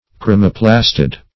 Search Result for " chromoplastid" : The Collaborative International Dictionary of English v.0.48: Chromoplastid \Chro`mo*plas"tid\, n. [Gr.